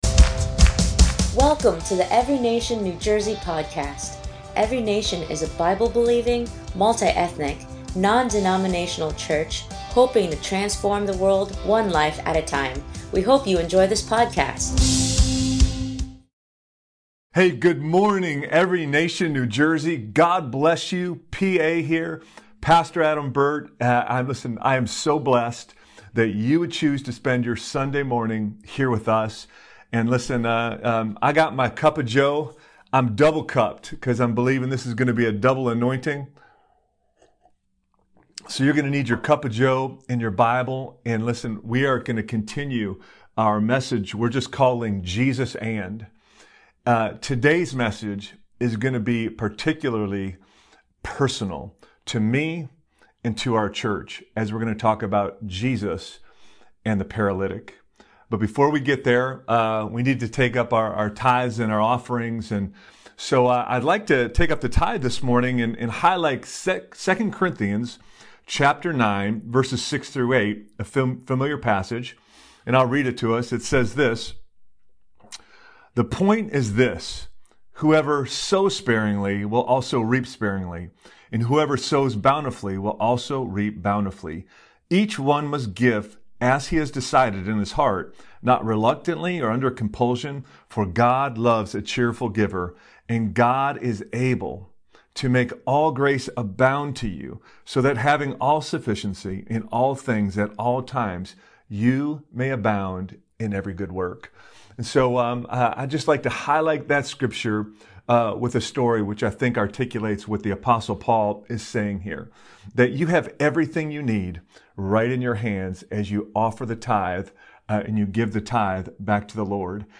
ENCNJ Sermon 2/14/21